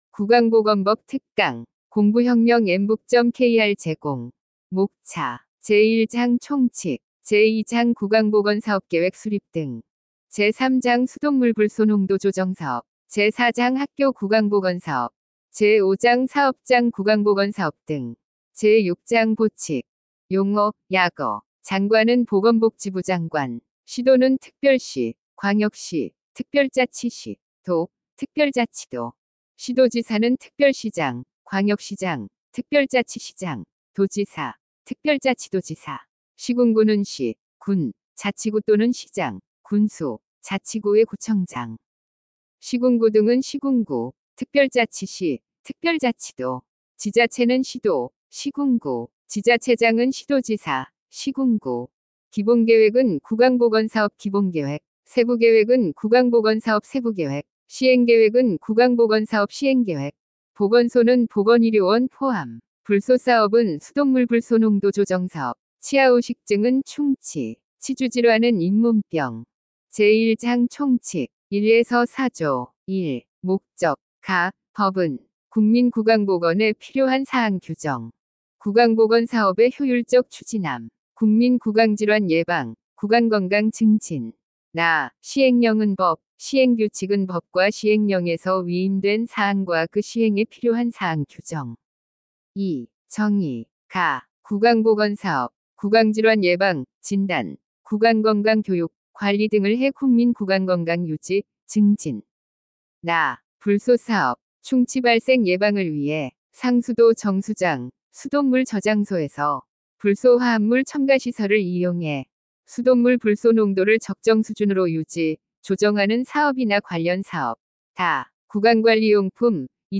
엠북학원은 우리나라 최초의 인공지능이 강의하는 사이버학원이며, 2025년 4월 28일 개원하였습니다.
구강보건법-특강-샘플.mp3